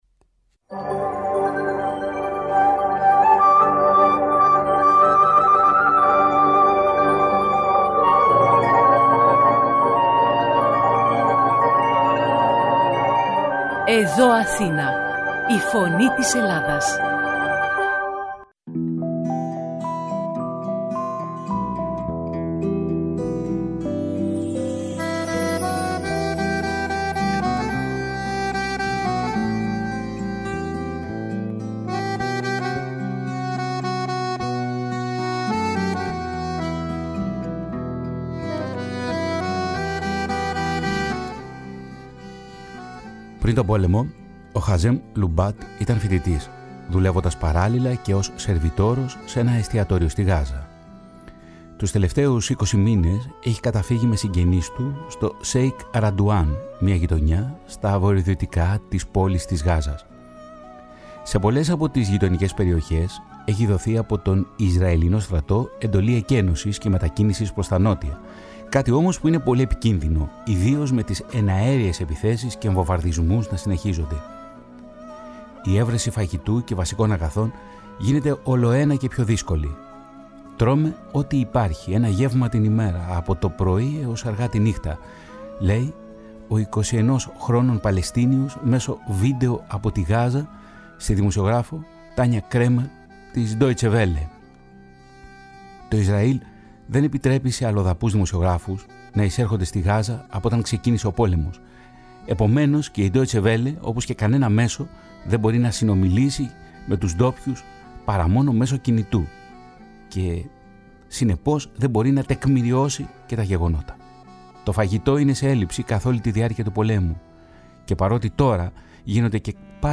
Το ραδιοφωνικό ντοκιμαντέρ πραγματοποιείται σε συνεργασία με το Διεθνές Ινστιτούτο Τύπου (International Press Institute).Το δεύτερο μέρος του ραδιοφωνικού ντοκιμαντέρ καταγράφει τις πολλαπλές όψεις της ανθρωπιστικής κρίσης που εκτυλίσσεται μπροστά στα μάτια της διεθνούς κοινότητας.